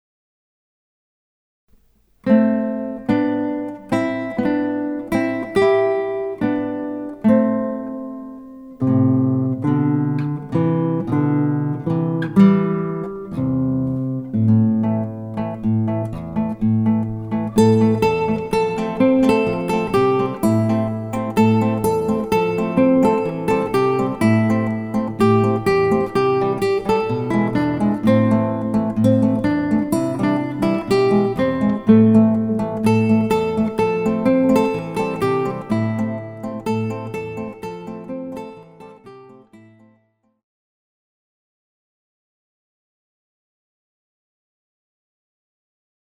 Guitar Duo
External link opens in new tab or windowDemo 1 (fades)